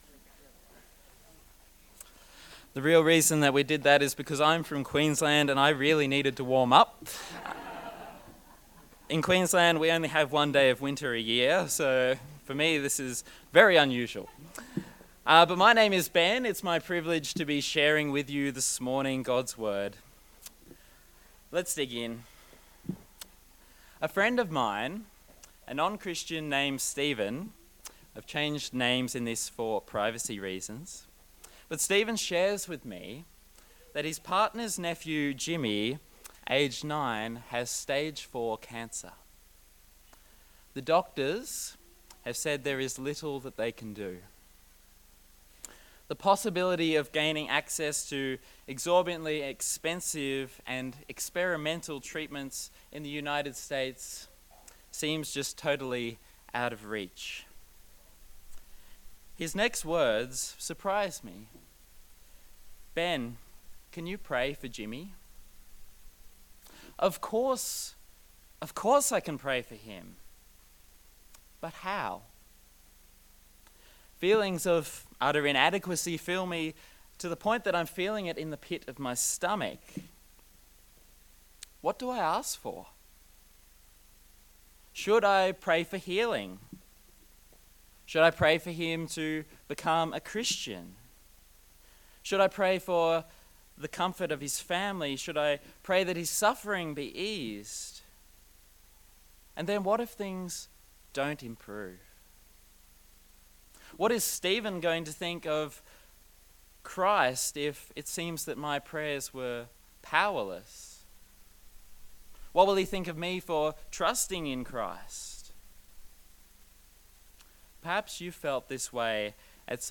Luke Passage: Luke 11:1-13 Service Type: Sunday Service